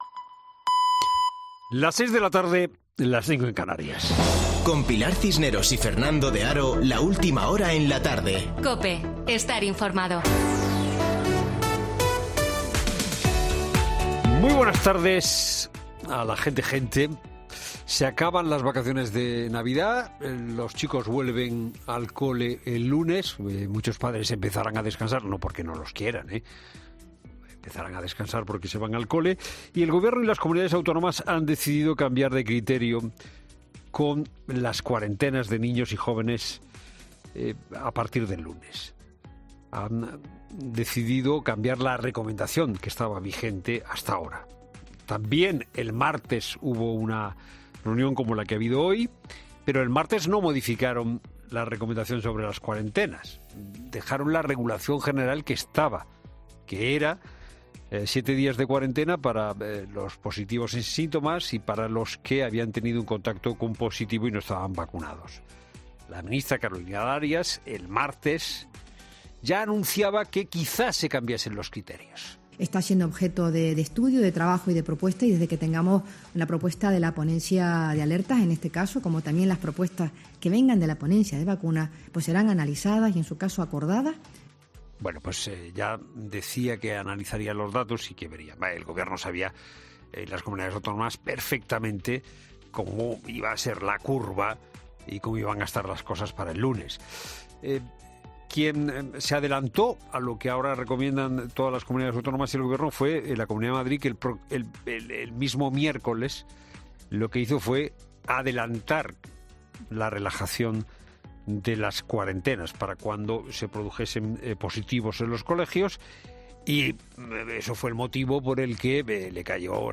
Boletín de noticias COPE del 7 de enero de 2022 a las 18:00 horas